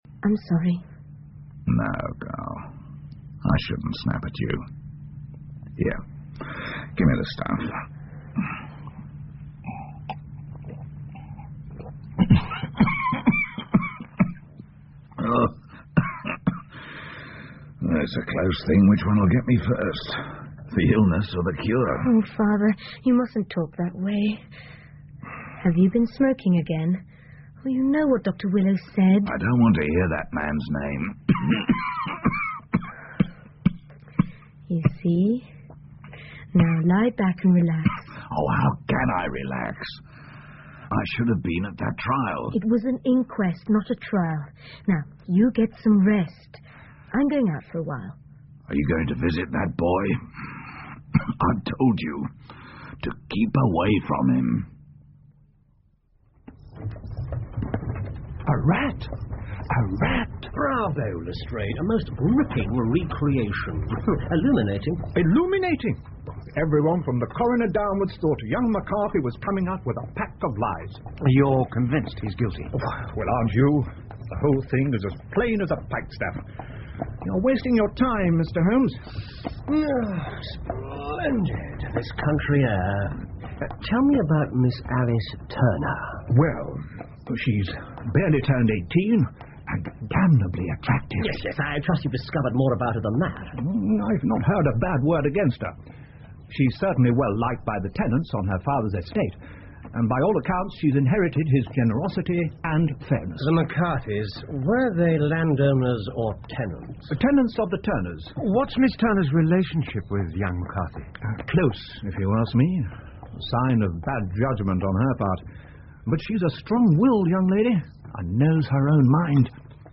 福尔摩斯广播剧 The Boscombe Valley Mystery 3 听力文件下载—在线英语听力室